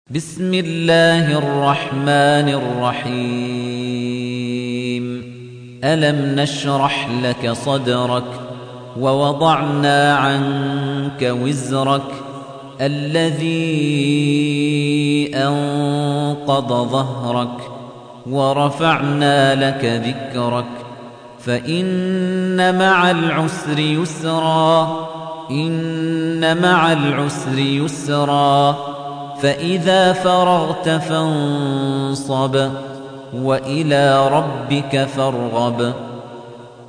تحميل : 94. سورة الشرح / القارئ خليفة الطنيجي / القرآن الكريم / موقع يا حسين